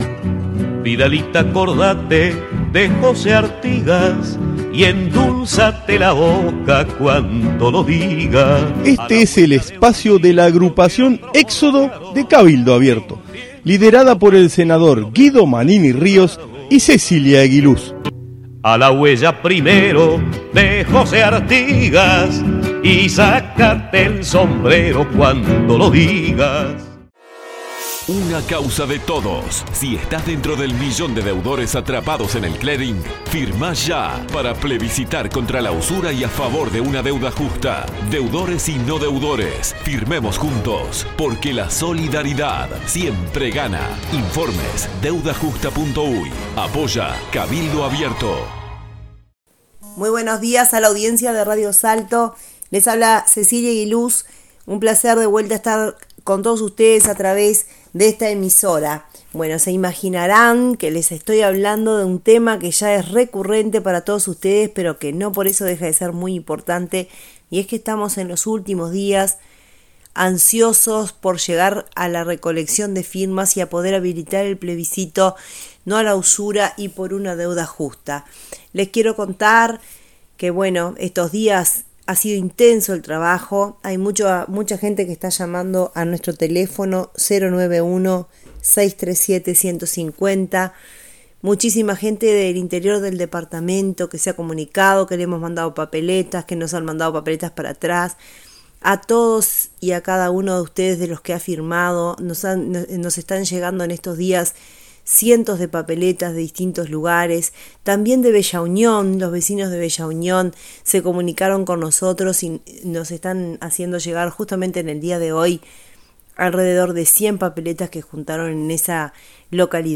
Audición radial de nuestra agrupación para Radio Salto(1120AM) del día 20 de Abril de 2024.